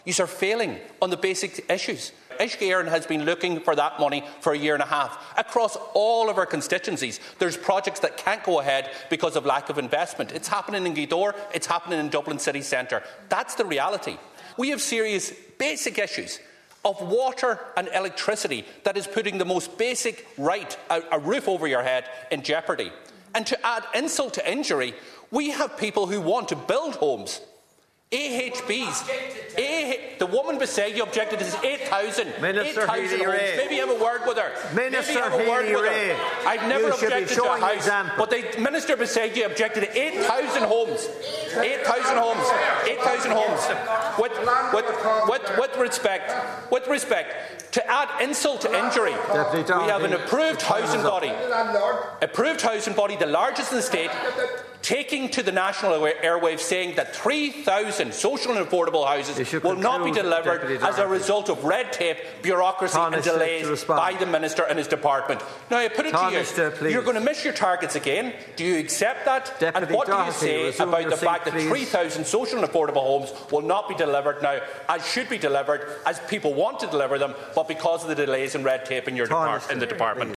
Deputy Pearse Doherty told Tanaiste Simon Harris that too much red tape is preventing the building of much-needed homes: